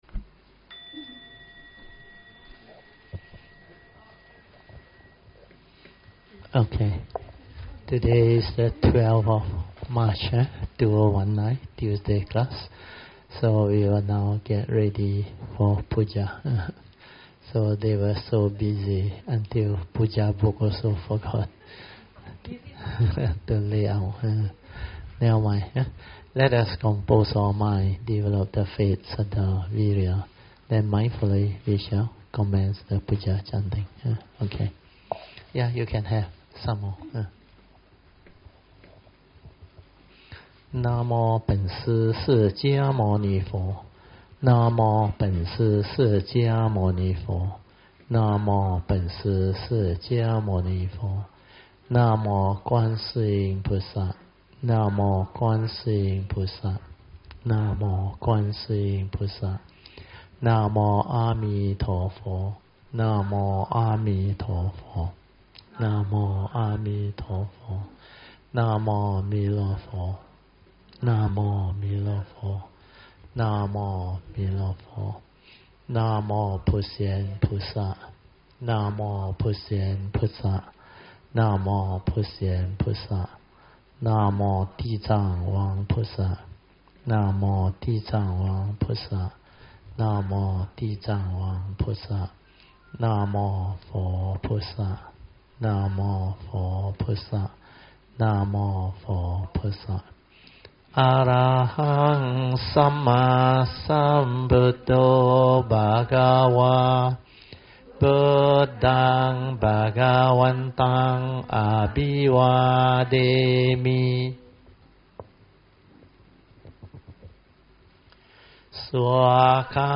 Tuesday Class